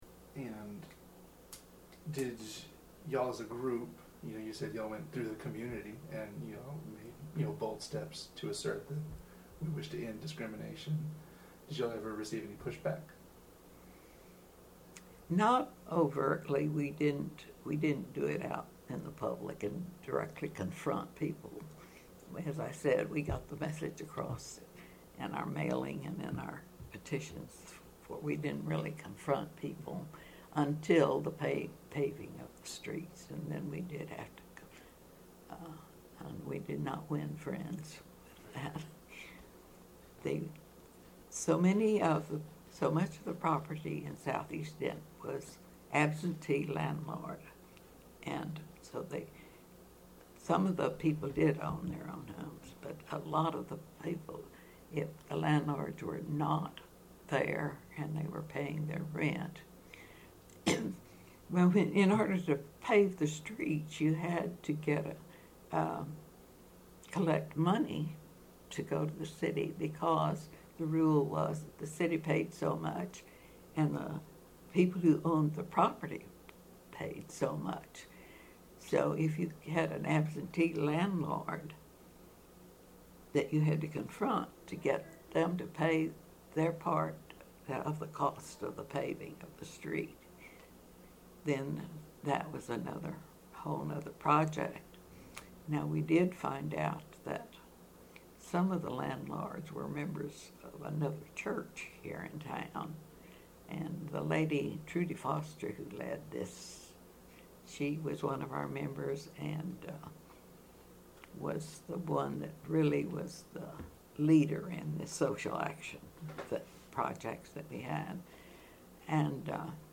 Excerpt of an Oral History Interview